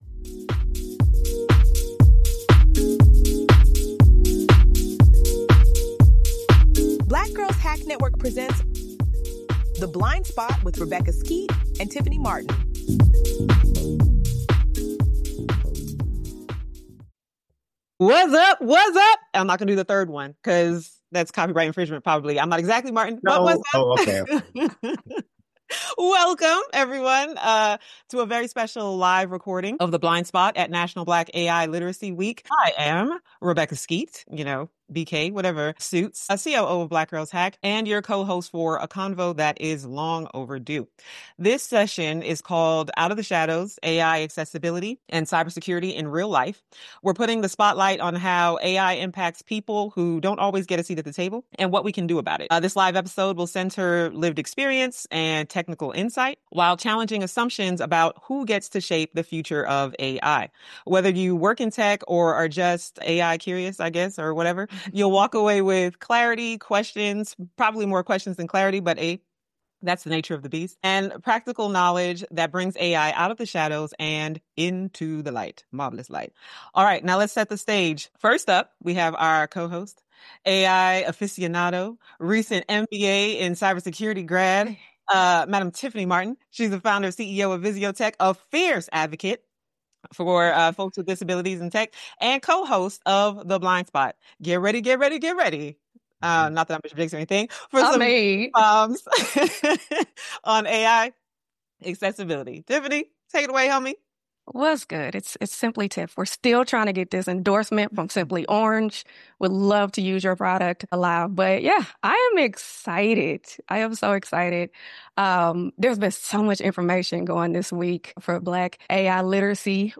The Blind Spot Live: National Black AI Literacy Week